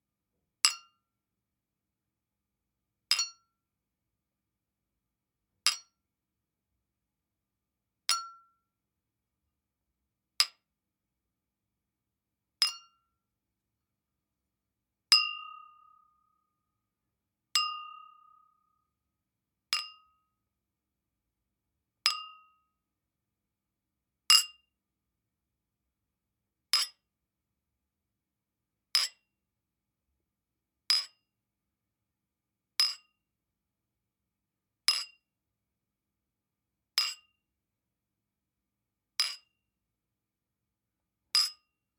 Glass Clink Pack
Breaking Breaking-Glass Broken Cleaning Clink Clinking Crack Cracking sound effect free sound royalty free Memes